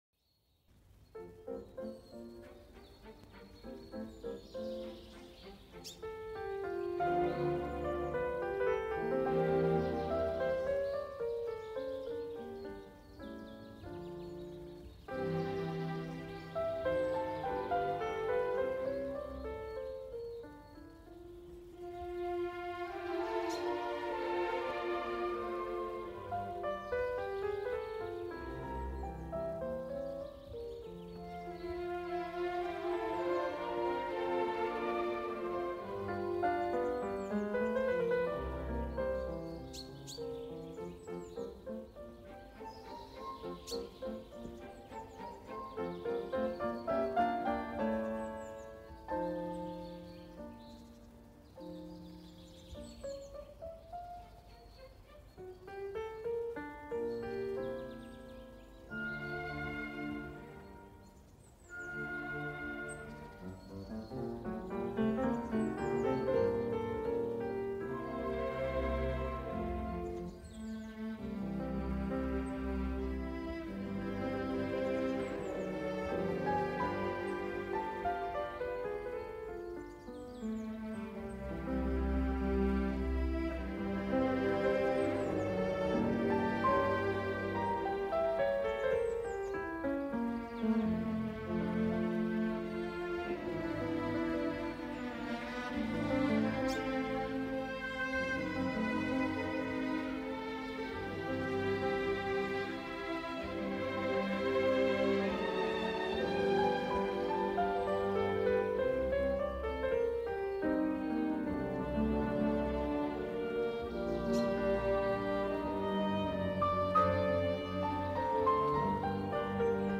Amsterdam Naturel : Pluie Légère